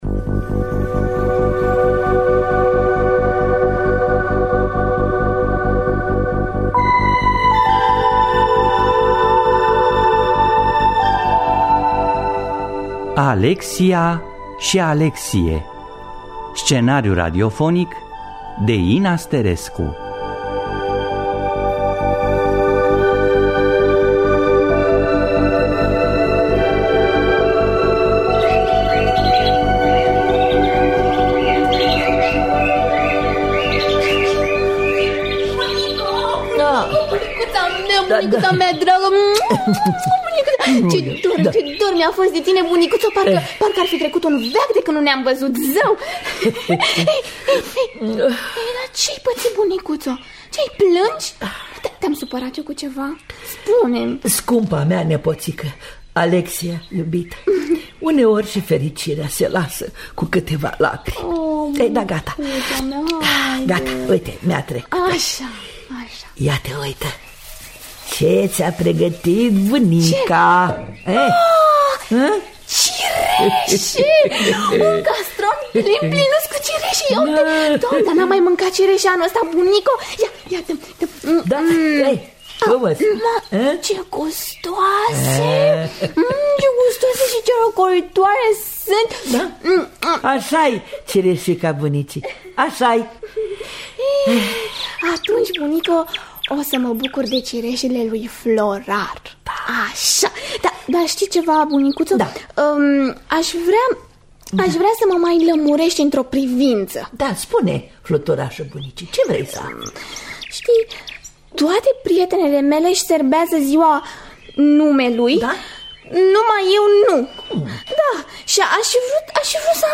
Scenariu radiofonic de Ina Sterescu.